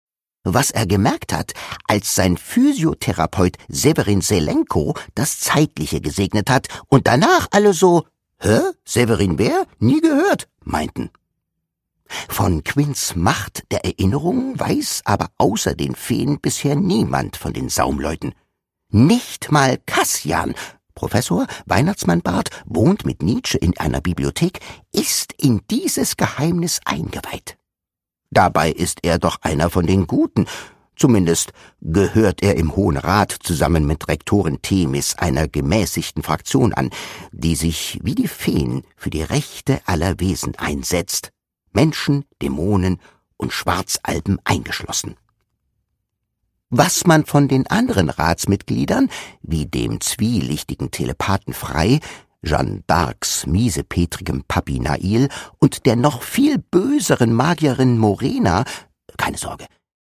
Produkttyp: Hörbuch-Download
Gelesen von: Timmo Niesner, Jasna Fritzi Bauer